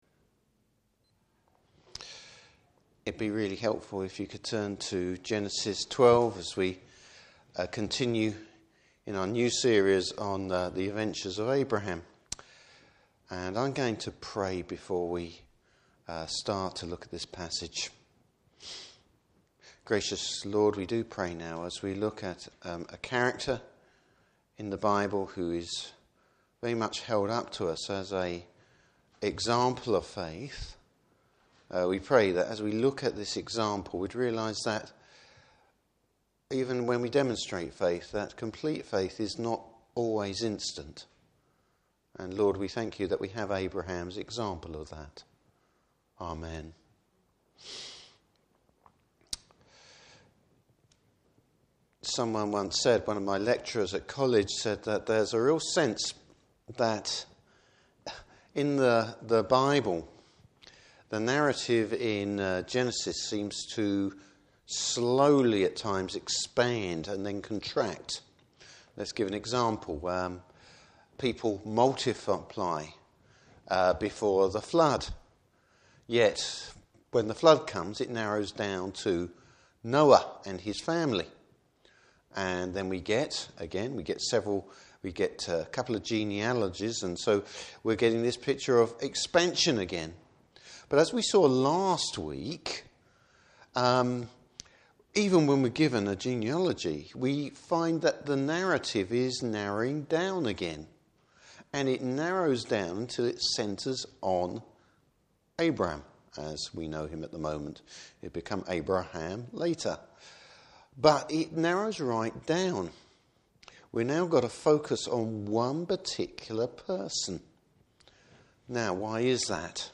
Service Type: Evening Service Abram both demonstrates and fails were faith is concerned.